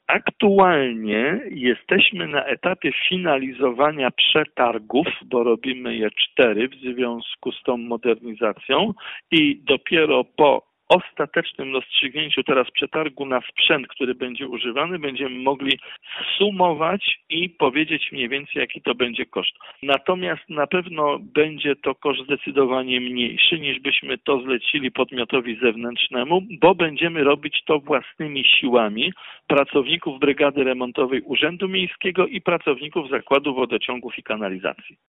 Remont ul. Farnej zakłada kompleksową modernizację, obejmującą nie tylko wymianę nawierzchni, ale także to co znajduje się pod nią, czyli cały system wodno-kanalizacyjny. Na chwilę obecną nie wiadomo jeszcze ile dokładnie będą kosztowały prace, tłumaczy burmistrz Jacek Idzi Kaczmarek.